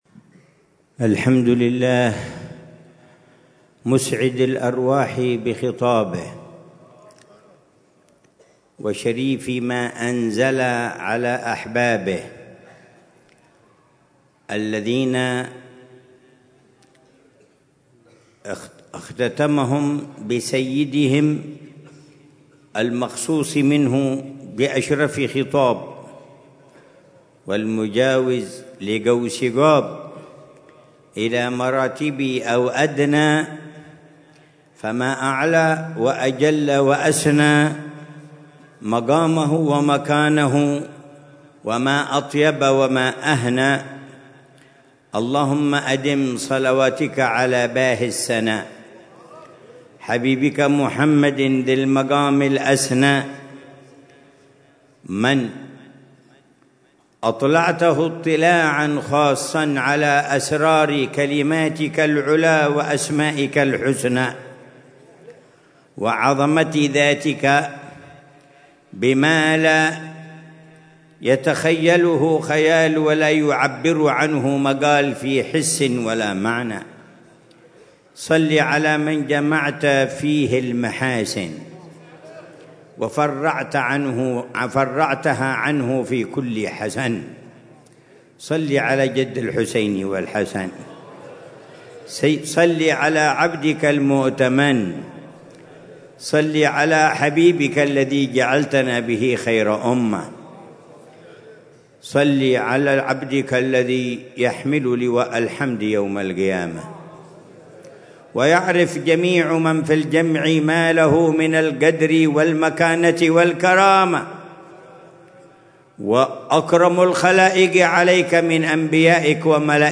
محاضرة العلامة الحبيب عمر بن محمد بن حفيظ ضمن سلسلة إرشادات السلوك في دار المصطفى، ليلة الجمعة 26 جمادى الآخرة 1446هـ، بعنوان: